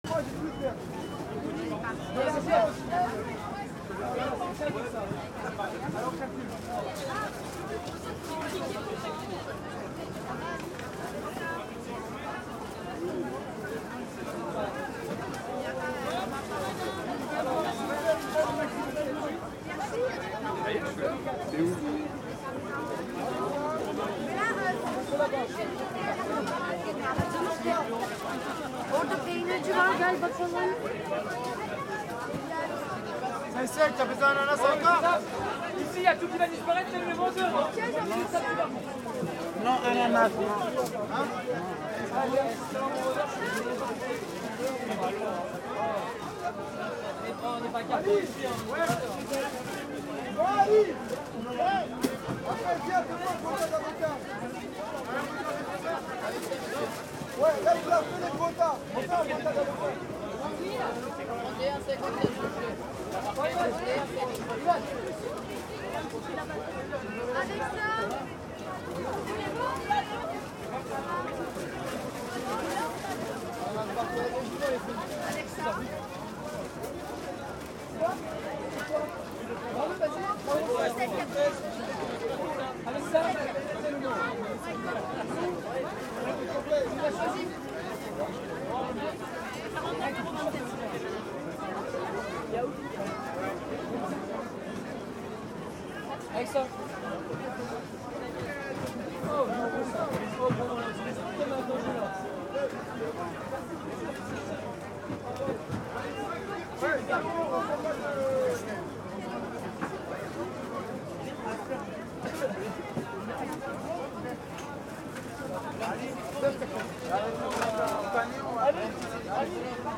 Market day in Neuilly-sur-Seine. People are speaking French, and I'm staring at a greengrocer.
Listen : Outdoor Market #4 (249 s)